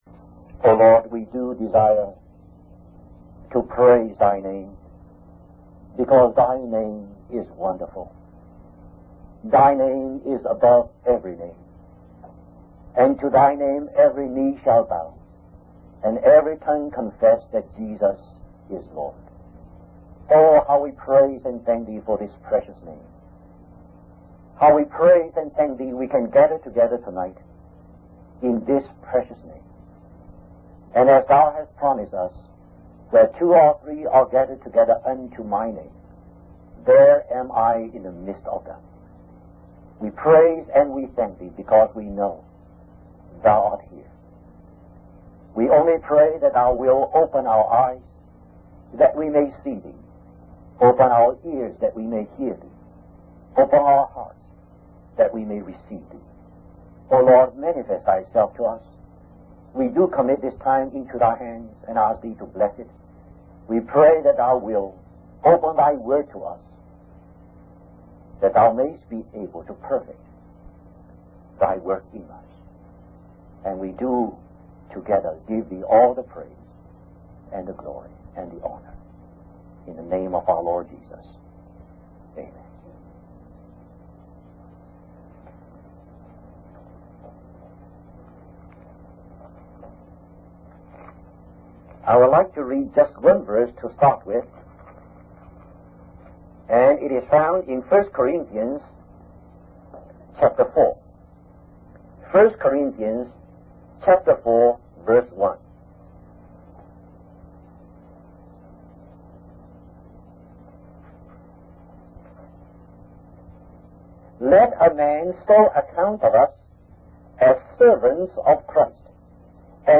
In this sermon, the preacher emphasizes the importance of being faithful to the grace that we have received from God. He uses the parable of workers in a field to illustrate this point, highlighting that God's grace is available to all, regardless of when they come to Him.